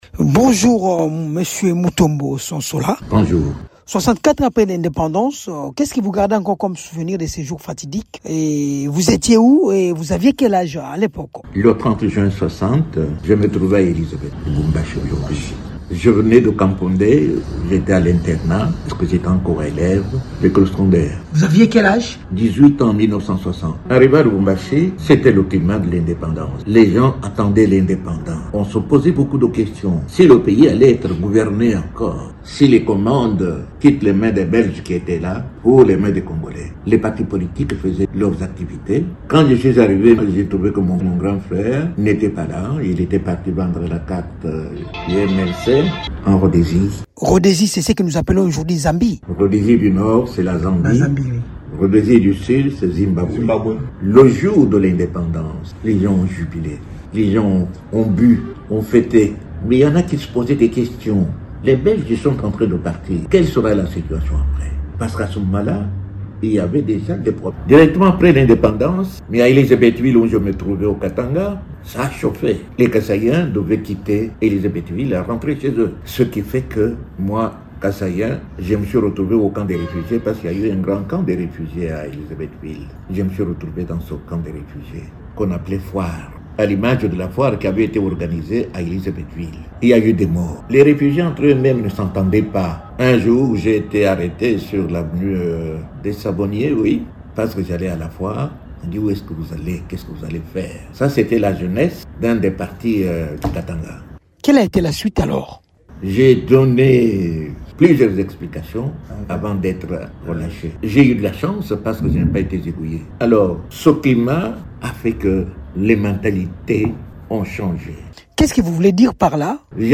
Il réagit au micro de